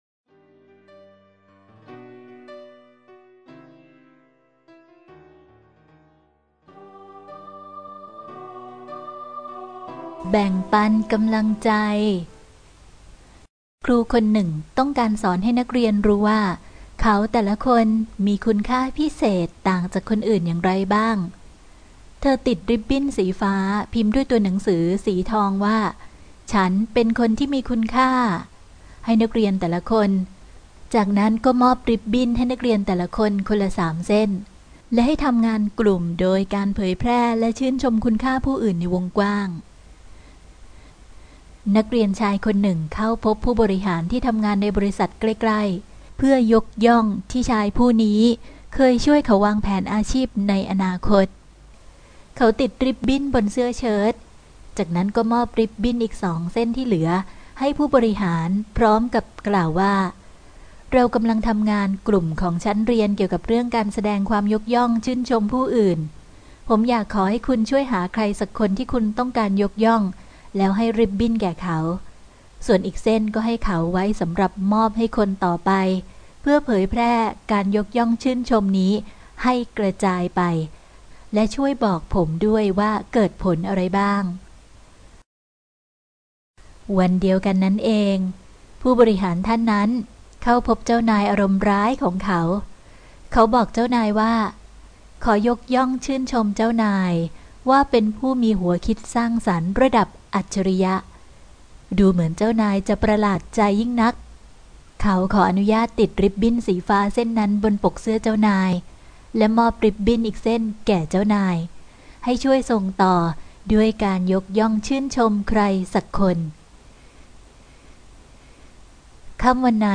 เสียงอ่านโดย